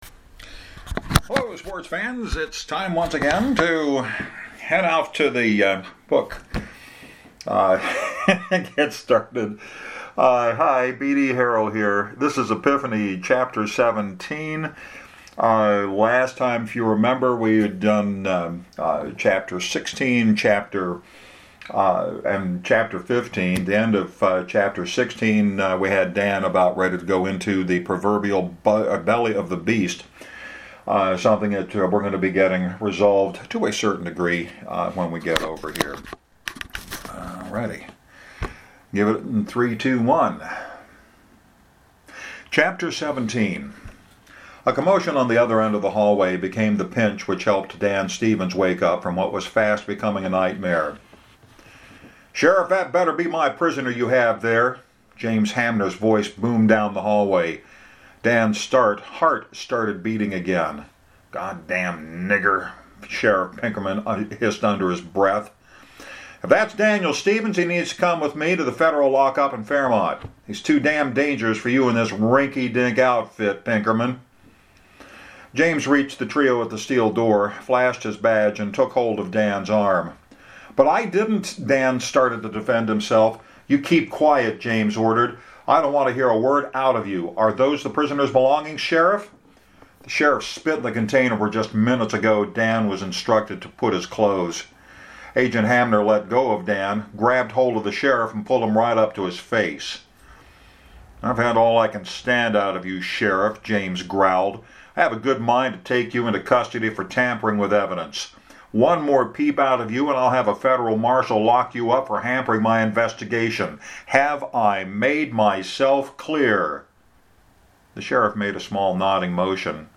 As usual, another chapter with some edits done on the fly. This time, we are covering Dan’s escape from the clutches of the Tucker County Sheriff.